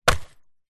Звуки ударов по дереву
Удар, треск тонкой доски